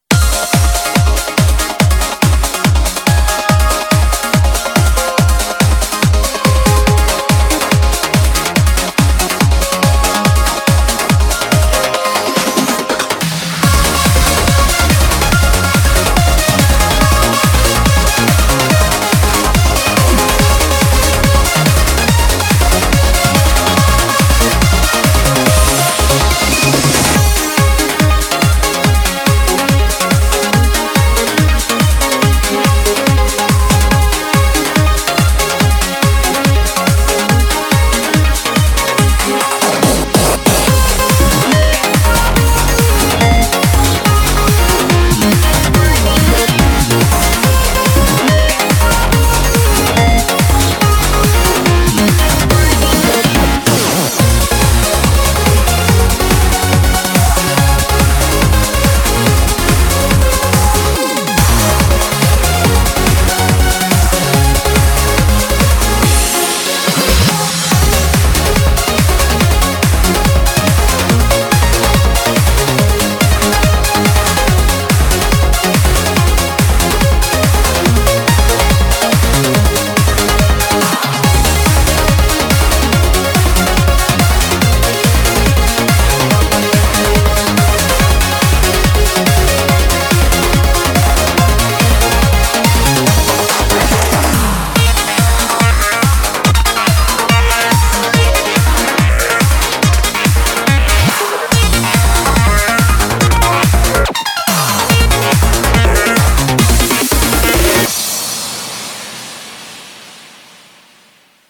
BPM142
Audio QualityPerfect (High Quality)
Comments[TRANCE]